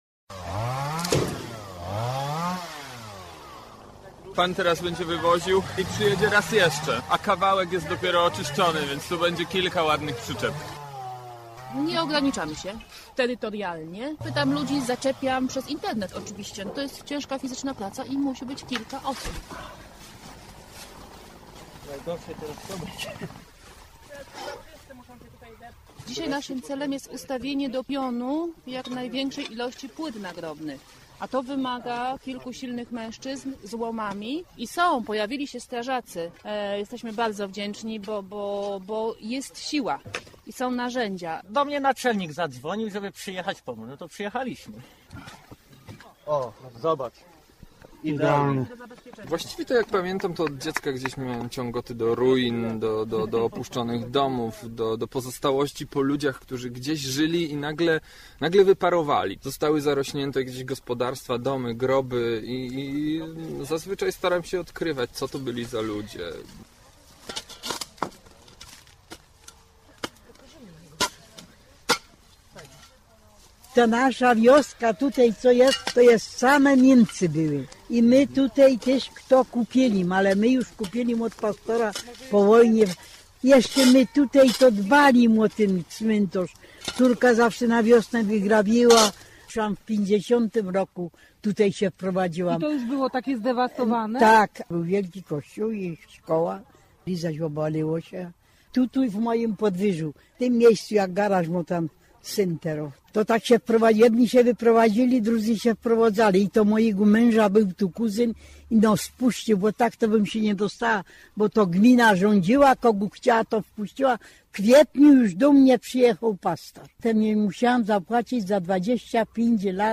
Grobersi - reportaż
553gcfojztmy0q8_reportaz_grobersi.mp3